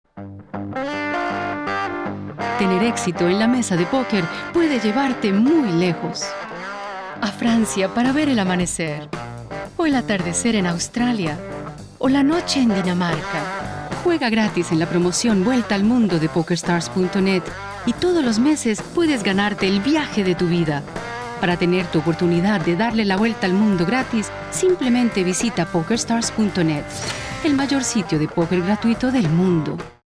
Colombian - Female
Commercial, Confident, Soft, Trustworthy